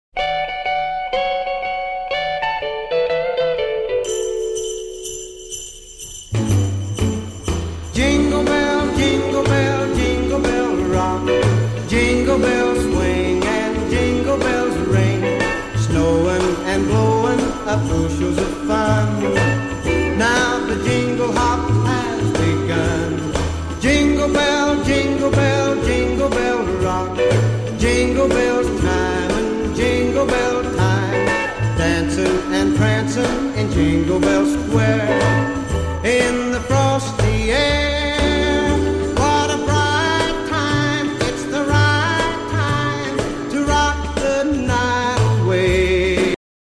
• Качество: 128, Stereo
праздничные
рождественские
rock n roll
50-е